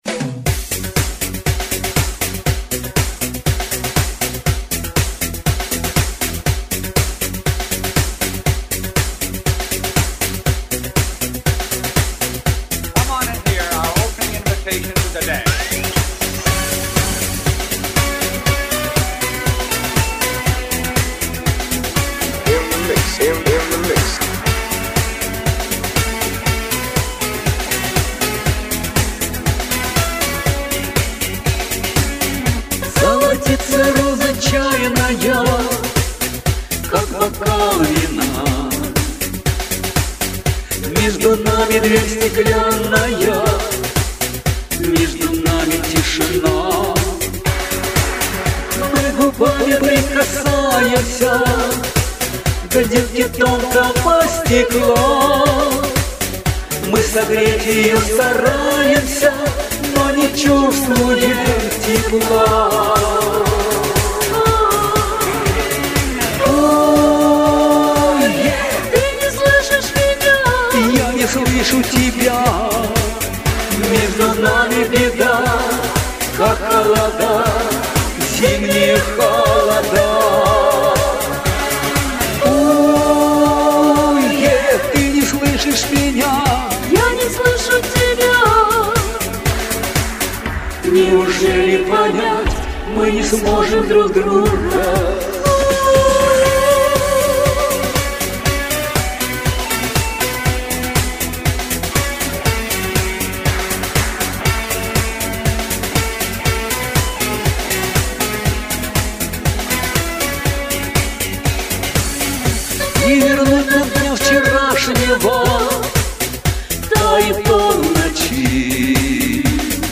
Качество записи у обоих, конечно, оставляет желать...